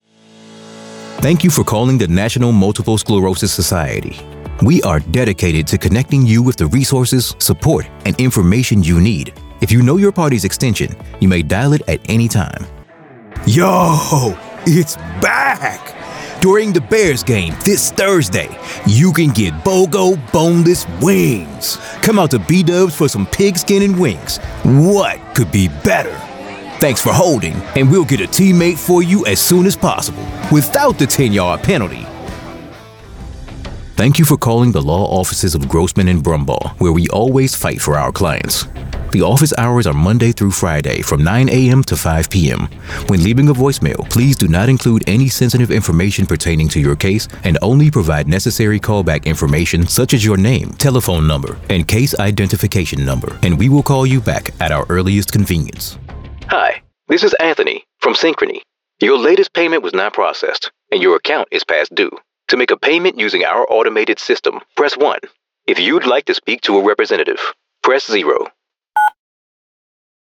IVR
Meine Stimme ist hymnisch, geerdet und unverkennbar echt – eine Mischung aus Textur, Kraft und Seele, die das Publikum berührt und Ihre Botschaft hervorhebt.
Sennheiser 416, Neumann U87, TLM 103